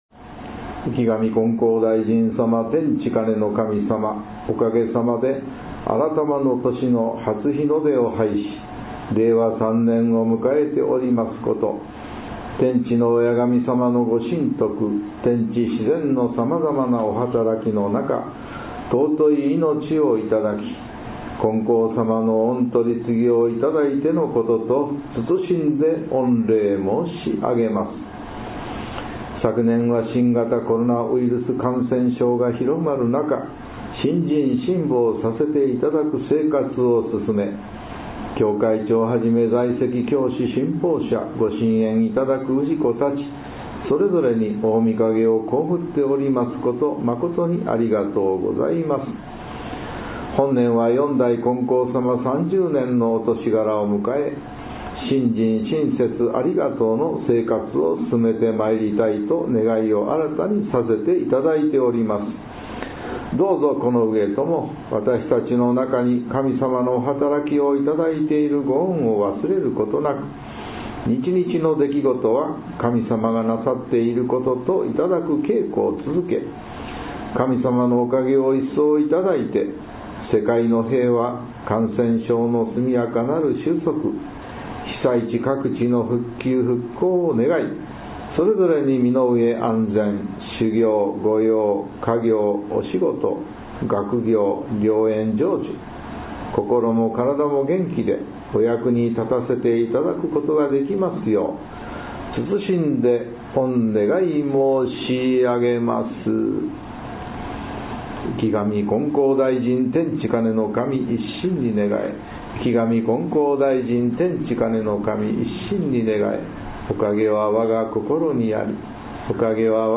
御礼を土台に一年の願いを立てる日 祭詞 R3.1.1 | 悩み相談・願い事祈願「こころの宮」